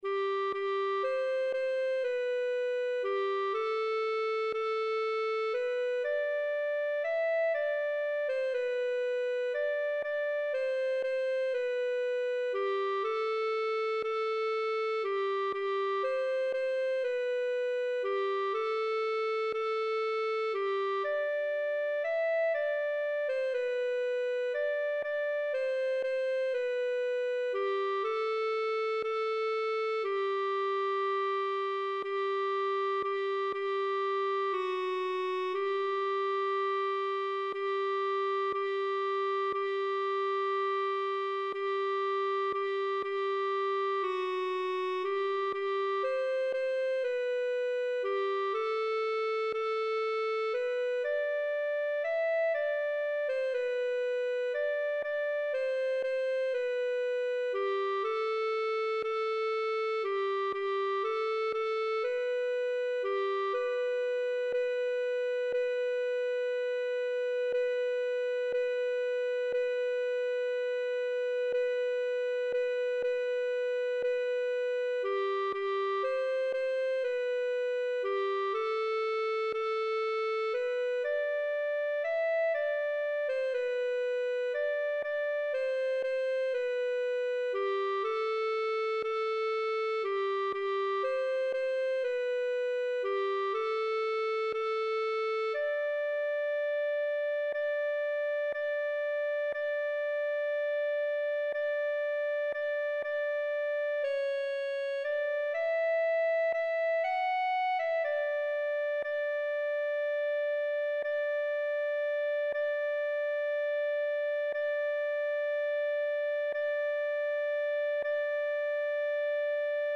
Voci (mp3): sopran, alto, tenor, bas, cor mixt